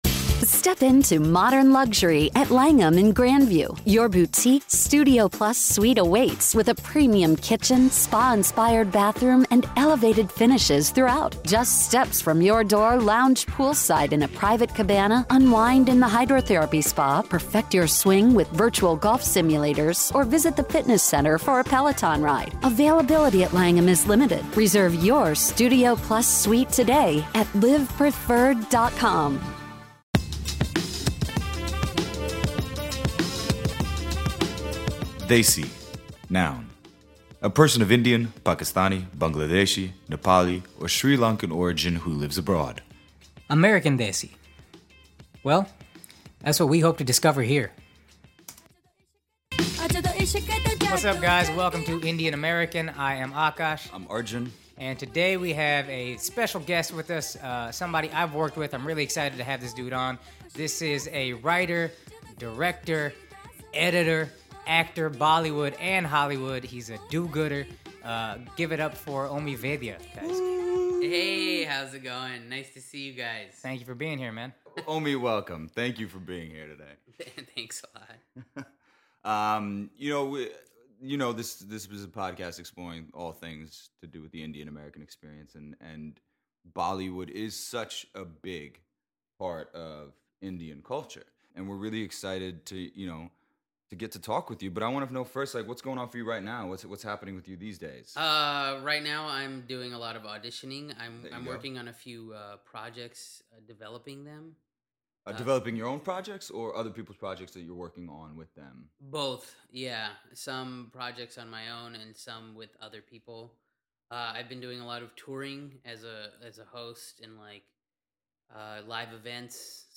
First up: Omi Vaidya, Bollywood Star.